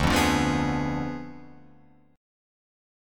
Ddim7 chord